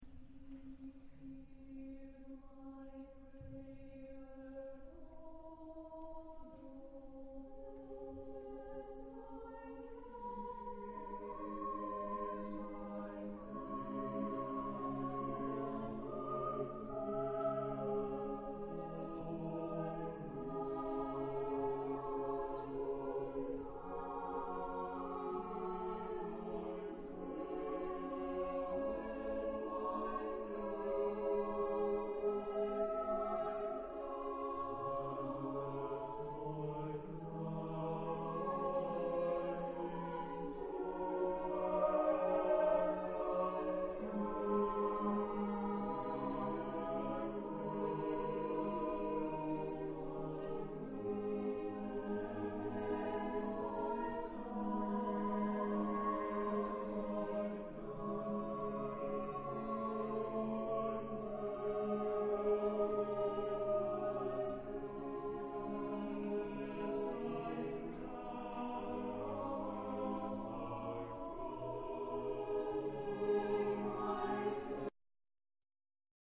Genre-Style-Form: Contemporary ; Vocal piece
Type of Choir: SSAATTBB  (8 mixed voices )